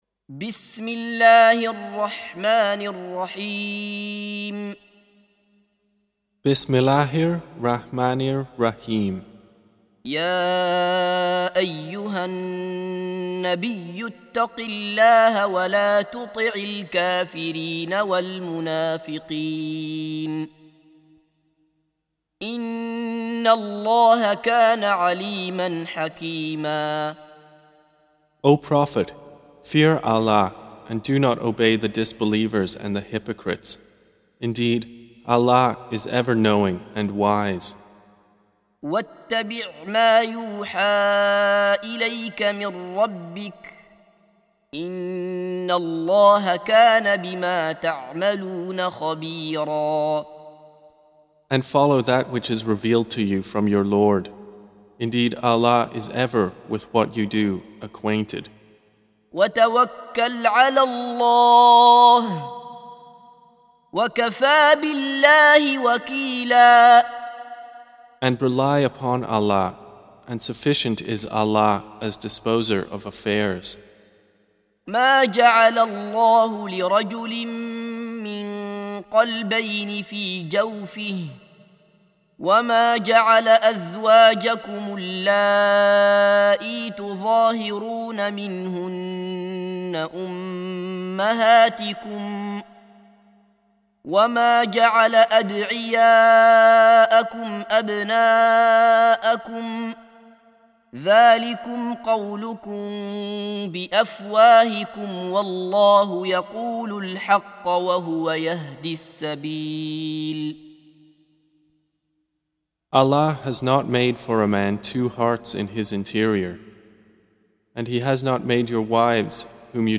Surah Repeating تكرار السورة Download Surah حمّل السورة Reciting Mutarjamah Translation Audio for 33. Surah Al�Ahz�b سورة الأحزاب N.B *Surah Includes Al-Basmalah Reciters Sequents تتابع التلاوات Reciters Repeats تكرار التلاوات